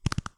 Horse Gallop 4.wav